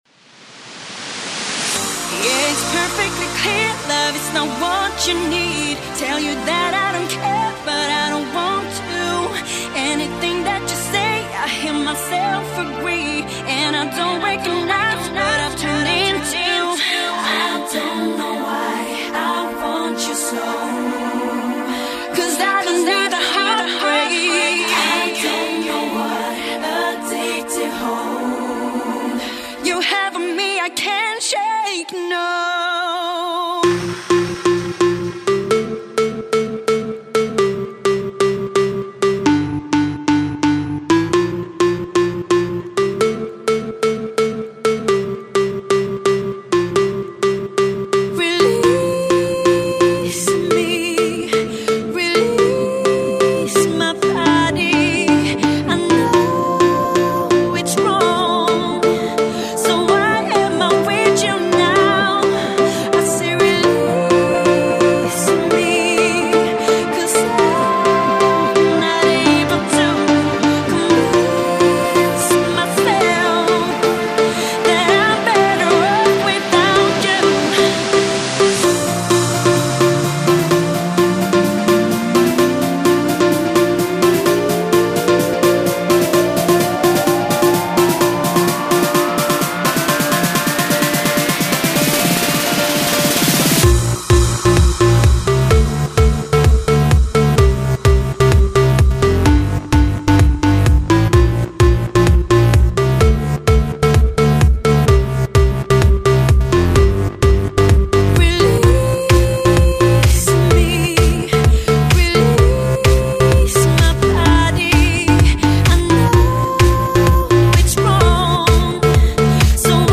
Жанр: Dance music